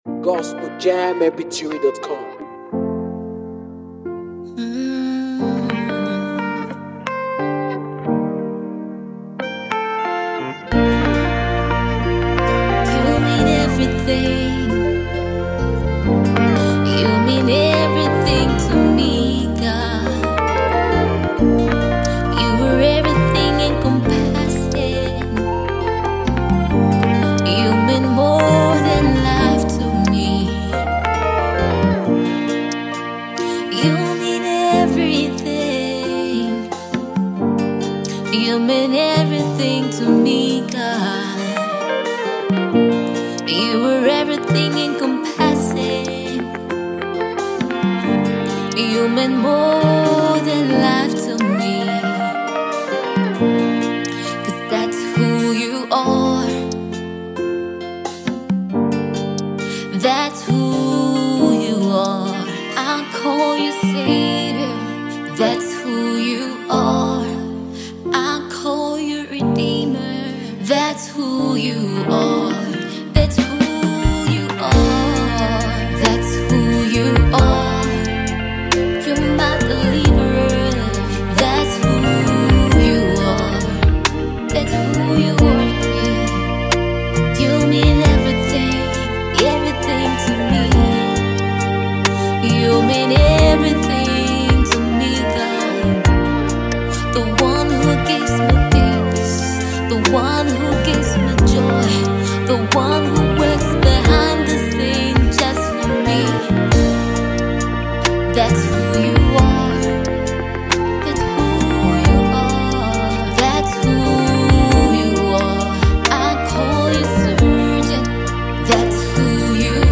NUMBER 1 AFRICA GOSPEL PROMOTING MEDIA